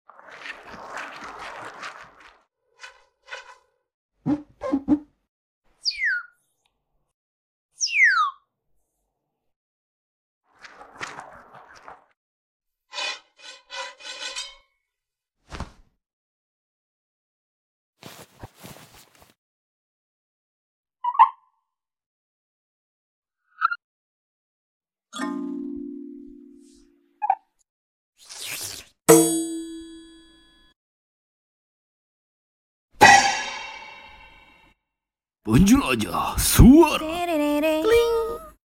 Menjelajah suara dari serial animasi sound effects free download